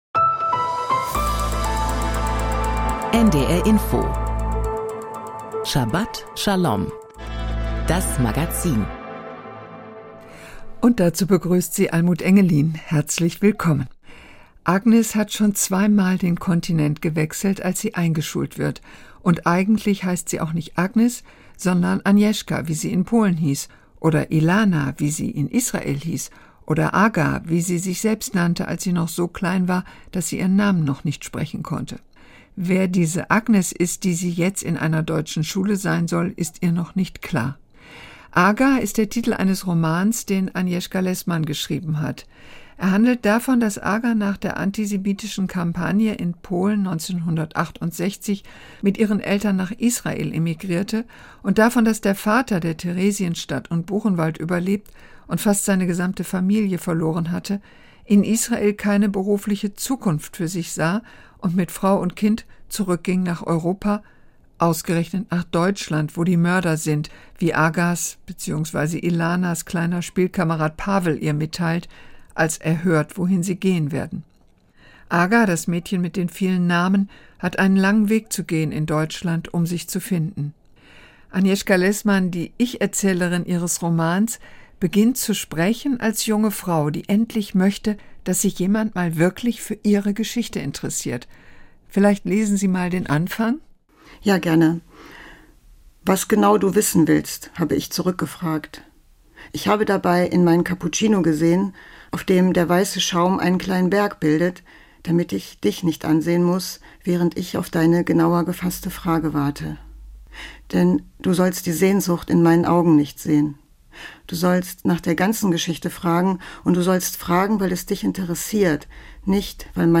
Heranwachsen als Kind eines Shoah-Überlebenden Interview